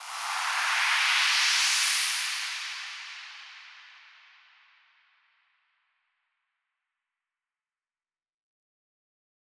Index of /musicradar/shimmer-and-sparkle-samples/Filtered Noise Hits
SaS_NoiseFilterA-05.wav